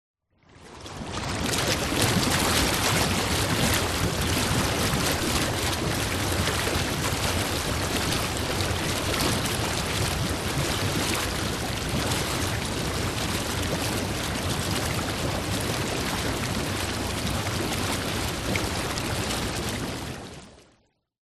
Звуки спа, атмосфера
Спа Джакузи включается работает недолго и отключается есть пузыри в воде